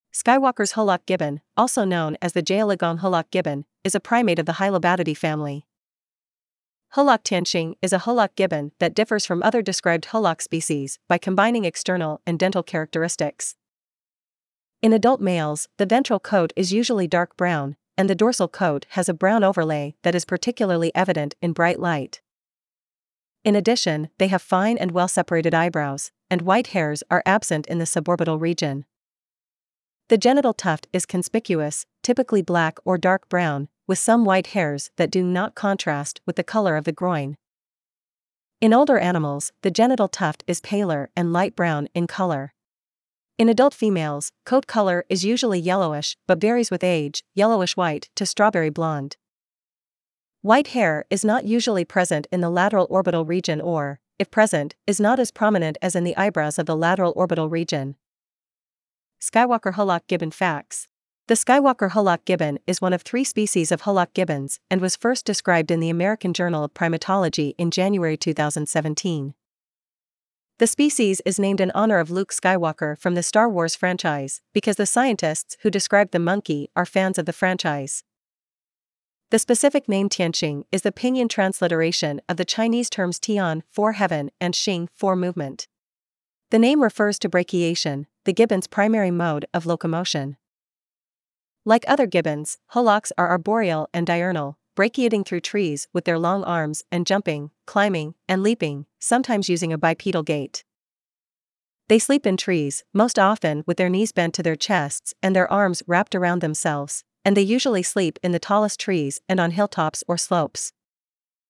Skywalker Hoolock Gibbon
Skywalkers-hoolock-gibbon.mp3